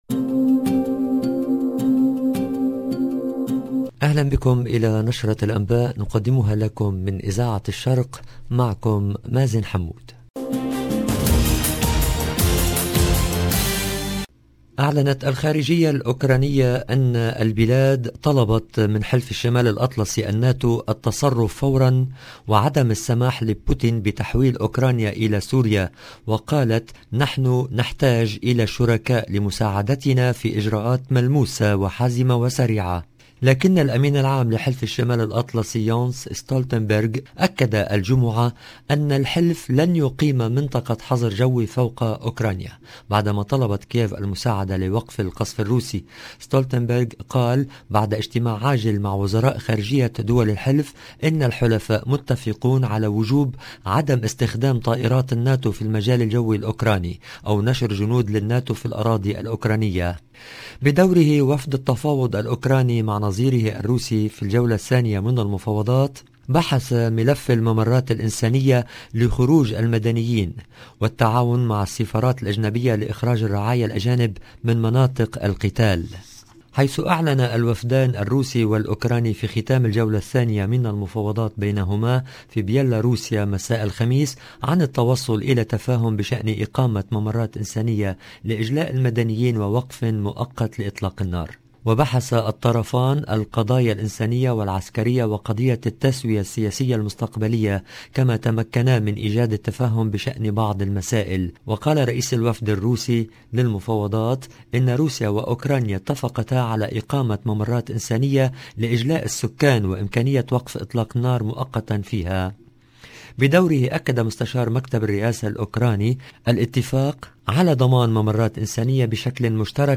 LE JOURNAL DU SOIR EN LANGUE ARABE DU 4/03/22